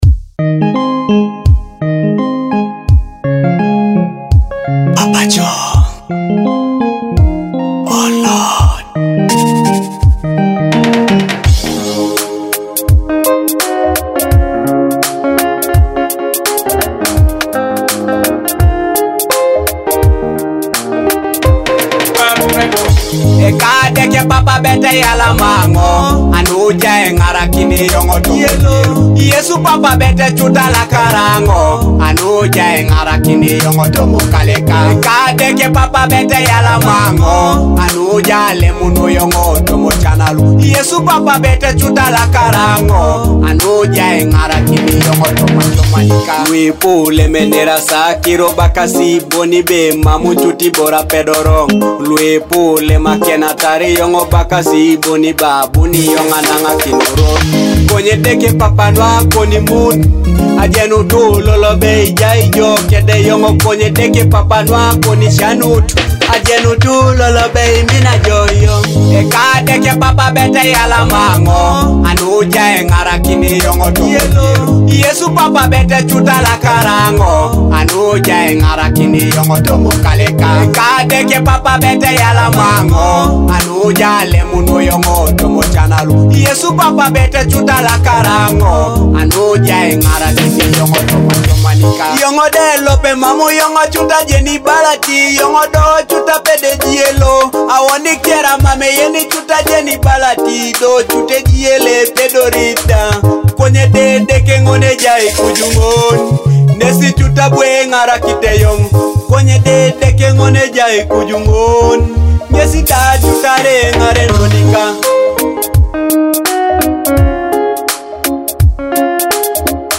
and feel the uplifting spirit of this must-have anthem.